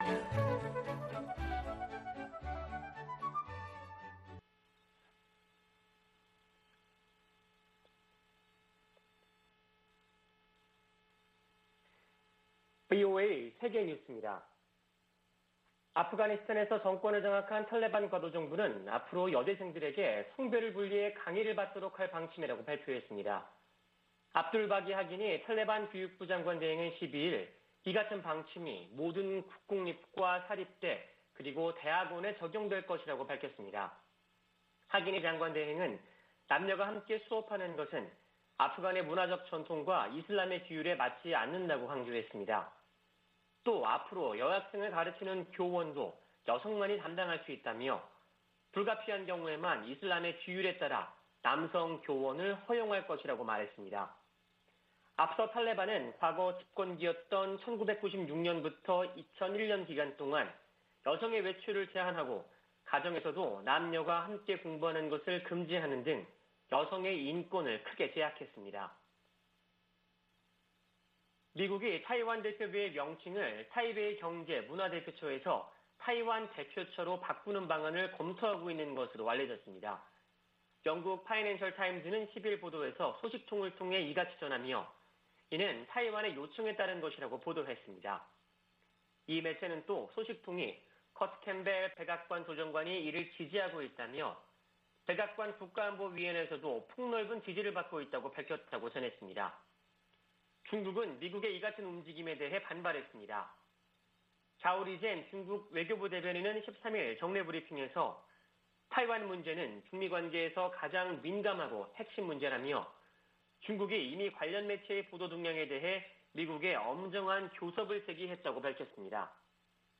VOA 한국어 아침 뉴스 프로그램 '워싱턴 뉴스 광장' 2021년 9월 14일 방송입니다. 북한이 미-한 연합훈련 반발 담화를 낸 지 한 달 만에 신형 장거리 순항미사일을 시험발사했다고 밝혔습니다. 미 인도태평양사령부는 북한의 미사일 발사에 관해 계속 상황을 주시할 것이고, 동맹ㆍ협력국들과 긴밀히 협의하고 있다고 밝혔습니다. 미국 전문가들은 한국이 개발하는 잠수함들이 북한을 억제하는데 도움이 될 것이라고 전망했습니다.